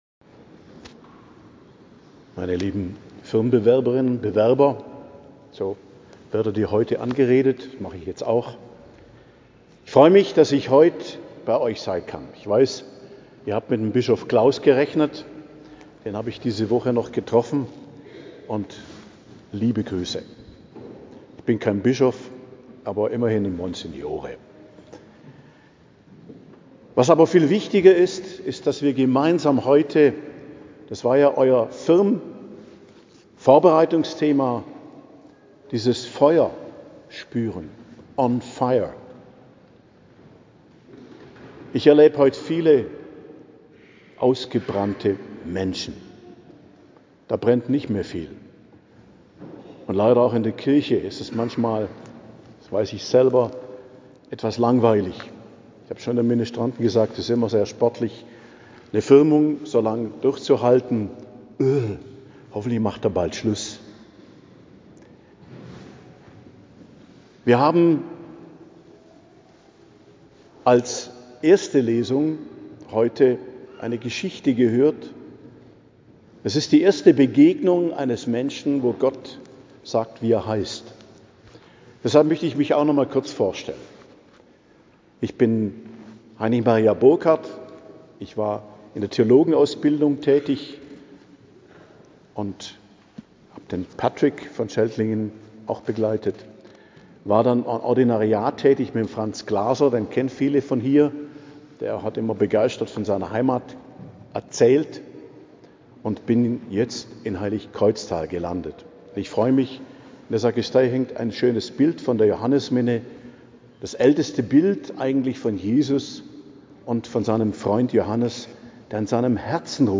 Predigt zum 14. Sonntag i.J. bei der Firmung in Schelklingen, 6.07.2025 ~ Geistliches Zentrum Kloster Heiligkreuztal Podcast